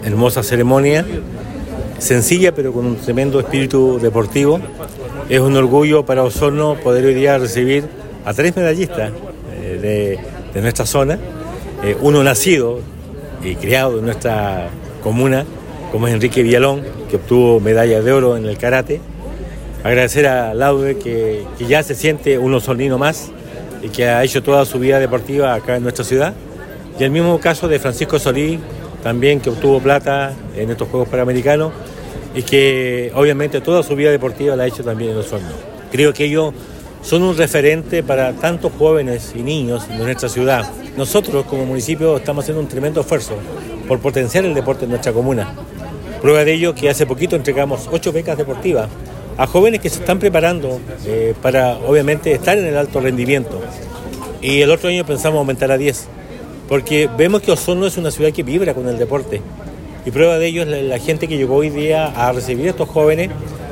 El Alcalde Emeterio Carrillo indicó que es un orgullo para Osorno poder recibirlos y felicitarlos públicamente por el logro deportivo, que son fruto de muchos años de trabajo y esfuerzo.